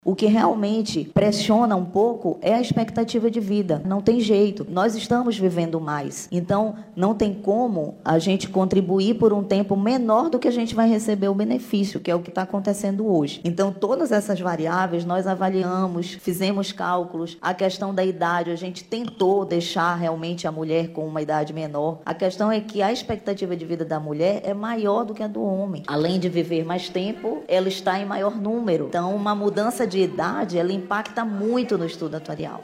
Em resposta, a diretora-presidente da Manaus Previdência, Daniela Benayon, argumentou que a medida é necessária, levando-se em conta a expectativa de vida das mulheres.